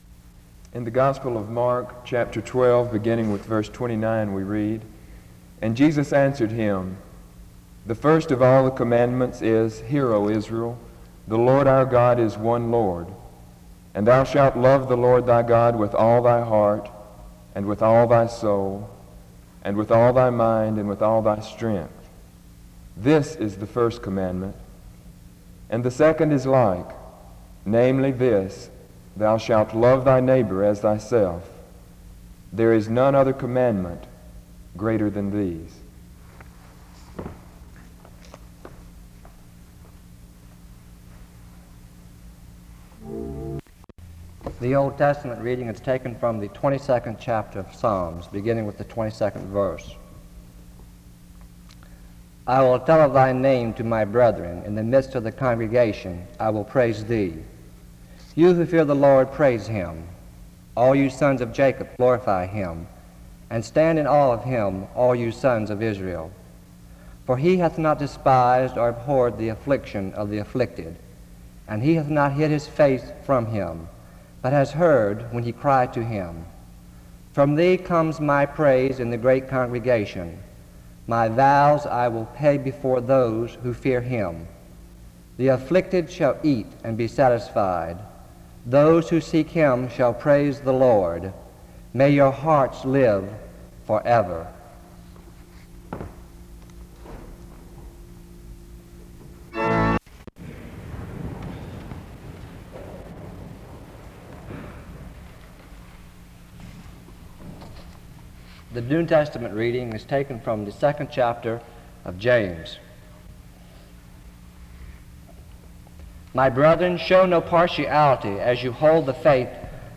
A responsive reading is read from 4:03-9:01. A call to recommit to Christ is given from 9:18-14:09. A closing prayer is offered from 14:13-16:06.
In Collection: SEBTS Chapel and Special Event Recordings SEBTS Chapel and Special Event Recordings - 1960s Miniaturansicht Titel Hochladedatum Sichtbarkeit Aktionen SEBTS_Chapel_Student_Service_1968-01-03.wav 2026-02-12 Herunterladen